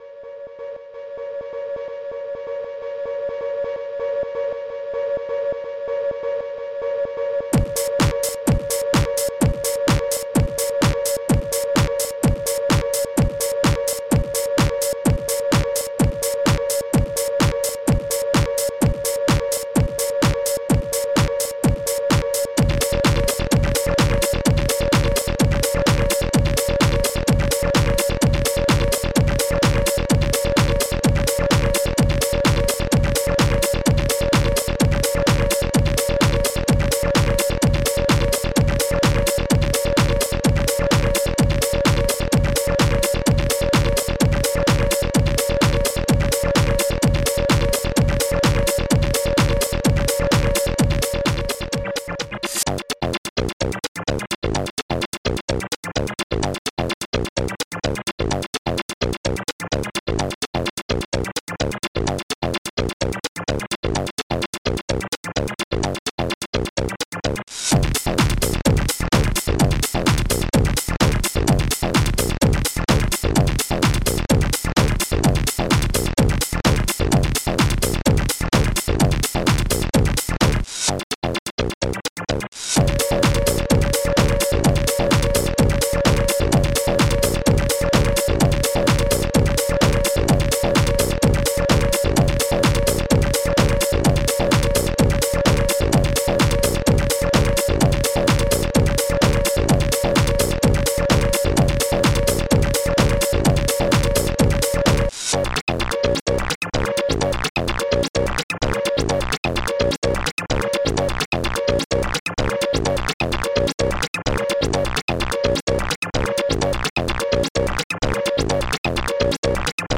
Protracker and family
HIHAT99.1
AIRATTACK-SIRENE
SYNTHKLING1
0909.CLAP
BREAKBEAT1
DRUMLOOP4